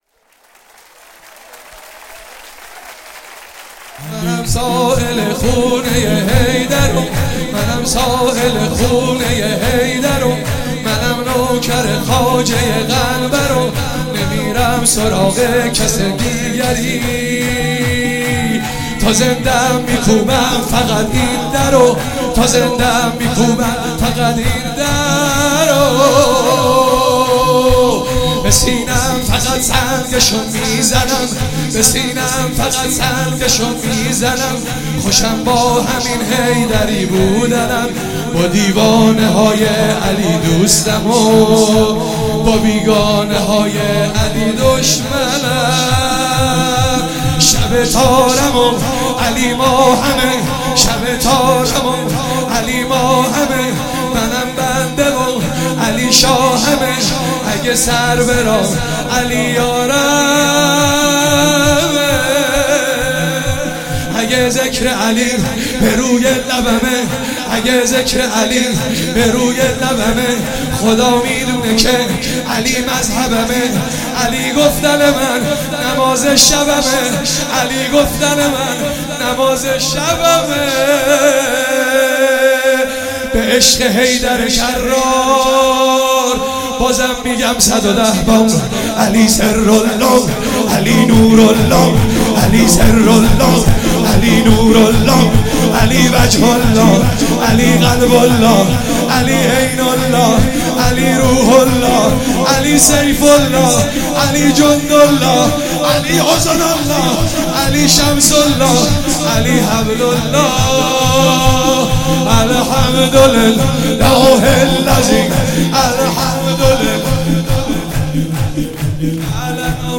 مراسم عید غدیر 16 شهریور 96
چهاراه شهید شیرودی حسینیه حضرت زینب (سلام الله علیها)
شور